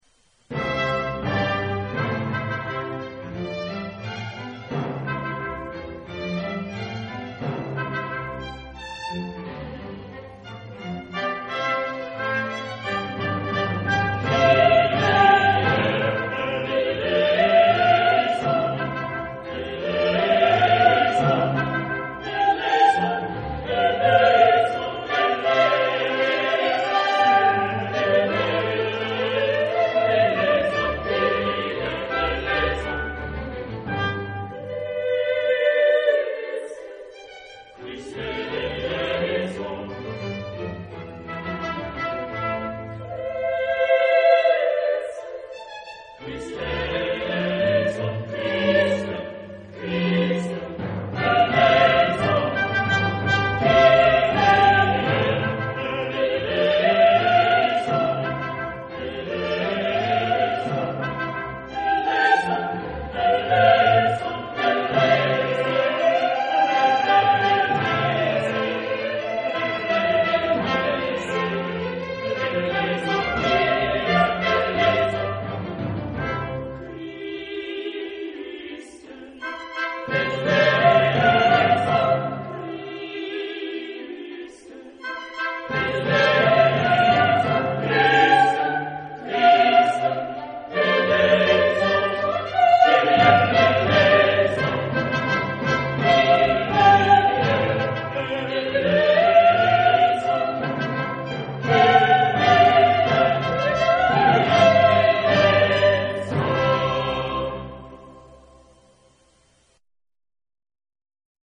Genre-Style-Forme : Sacré ; Messe ; Classique
Type de choeur : SATB  (4 voix mixtes )
Solistes : SATB  (4 soliste(s))
Instrumentation : Orchestre  (9 partie(s) instrumentale(s))
Instruments : Violon (2) ; Alto (1) ; Violoncelle (1) ; Contrebasse (1) ; Trompette en ut (2) ; Timbale (2) ; Orgue (1)
Tonalité : do majeur